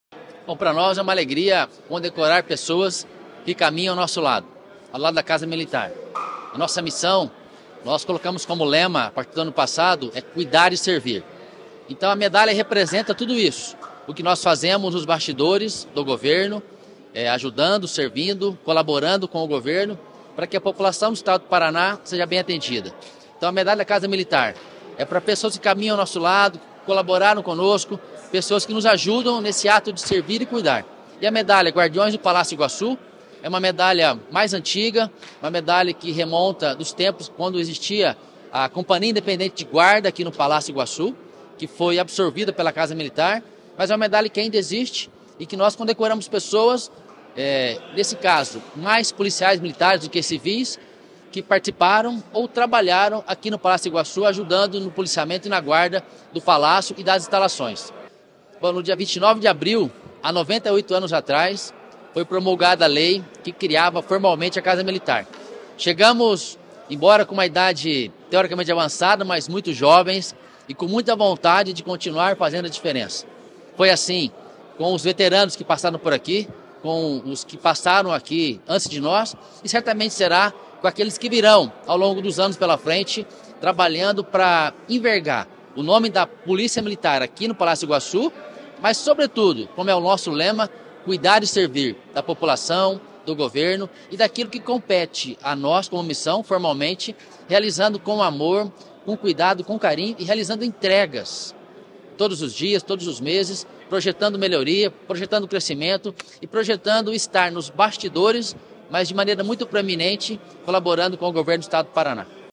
Sonora do chefe da Casa Militar, coronel Marcos Tordoro, sobre a entrega das Medalhas de Mérito da Casa Militar e das Medalhas de Mérito Guardiões do Palácio Iguaçu